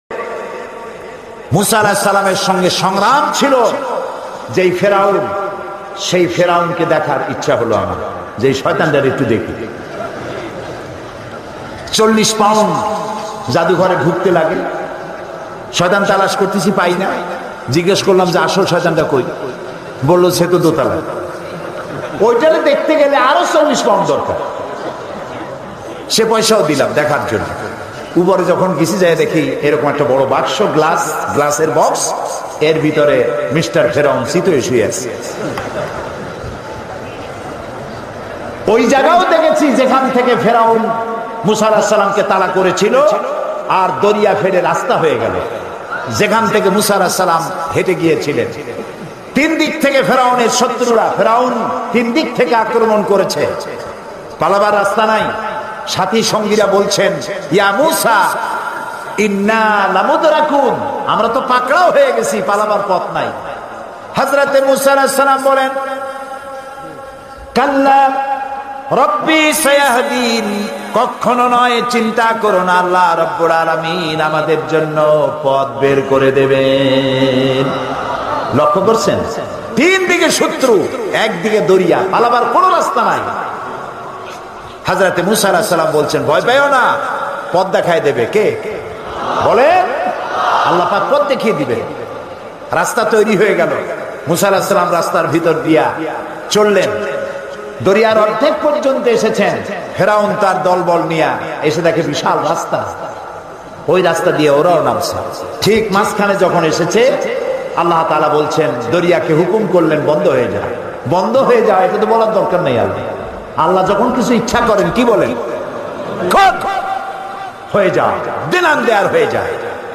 ফেরাউন ও হজরত মূসা (আ.) এর ইতিহাস দেলোয়ার হোসেন সাঈদীর ওয়াজ